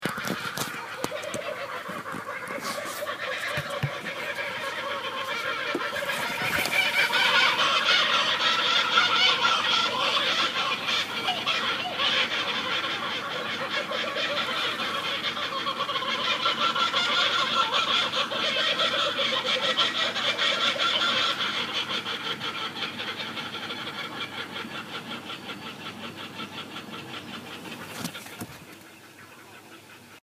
Um 05:42 heute morgen ging der Wecker auf dem Campground los. Die Kookaburra begannen mit ihrem Weckruf. Einer beginnt mit seinem Ruf, die übrigen der Gruppe folgen unmittelbar danach.
Kookaburra Cairns.mp3